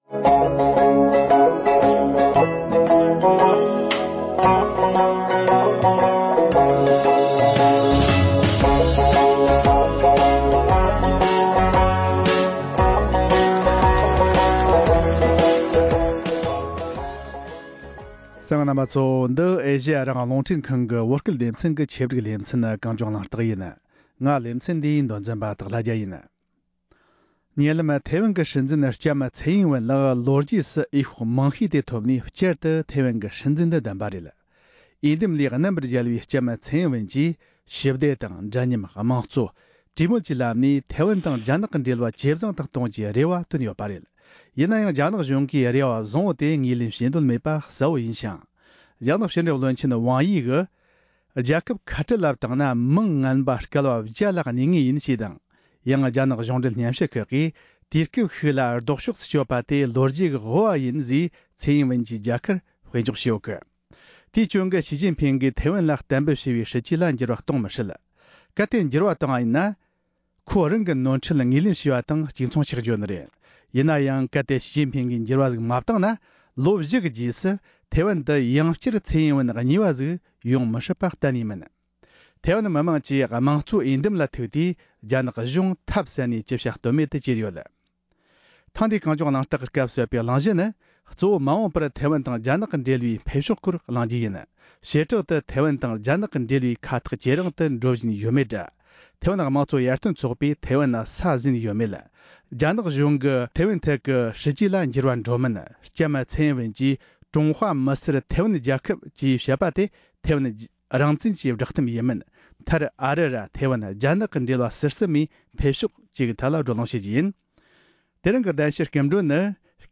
མ་འོངས་པར་ཐེ་ཝན་དང་རྒྱ་ནག་གི་འབྲེལ་བའི་འཕེལ་རྒྱས་དང་ཐེ་ཝན་དང་ལྷག་པར་དུ་ཐེ་ཝན་དང་རྒྱ་ནག་གི་འབྲེལ་བའི་ཁ་ཐག་ཇེ་རིང་དུ་འགྲོ་བཞིན་ཡོད་མེད་ཐད་གླེང་མོལ།